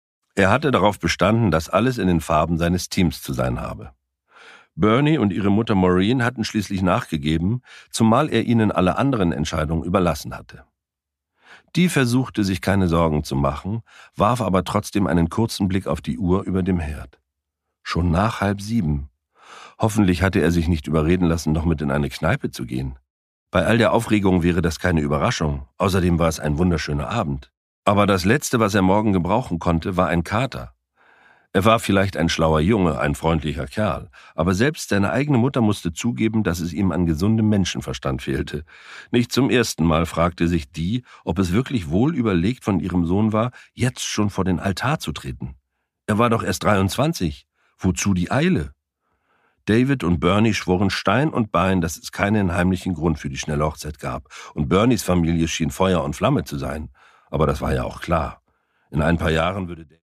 Produkttyp: Hörbuch-Download
Gelesen von: Charly Hübner